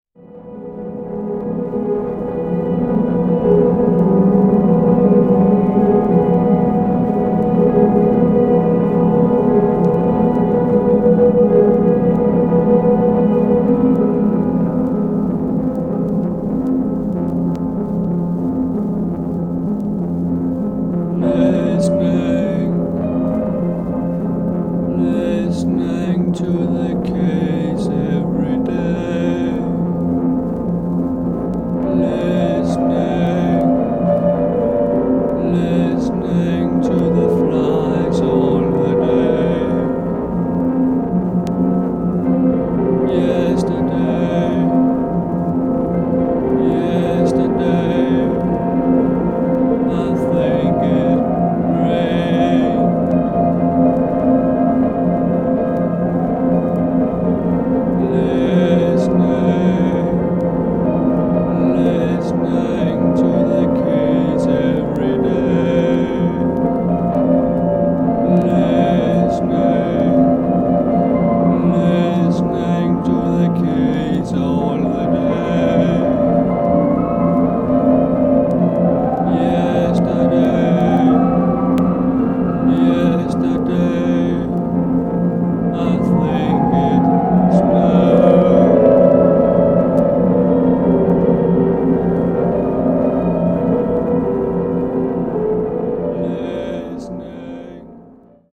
執拗に打ち返す打楽器の強迫的ビートとひずみ果てた轟音の危うい不調和が醸し出す、退廃的イメージの木霊に覆われた全7曲。
キーワード：サイケ　ミニマル　脱線パンク　即興